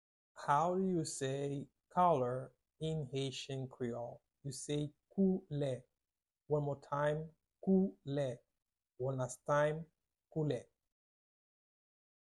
Pronunciation:
7.How-to-say-Color-in-Haitian-Creole-–-koule-with-Pronunciation.mp3